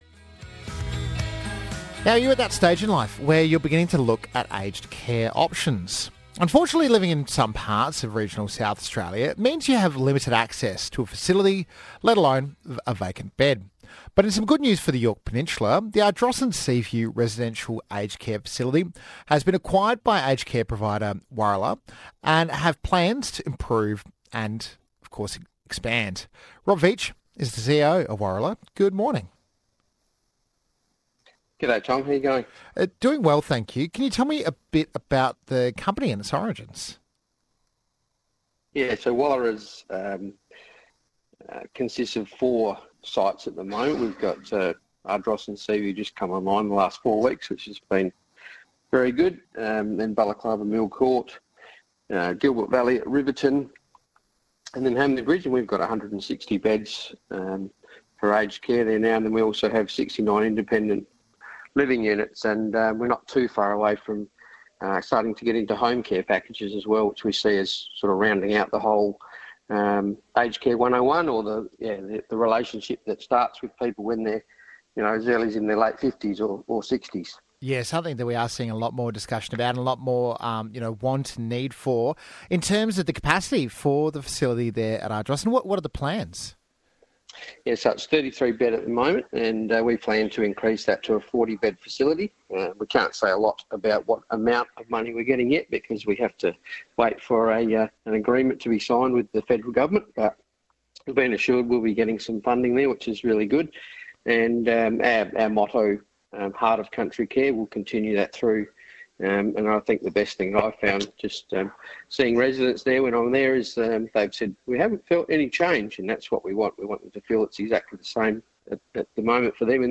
Summary of the Interview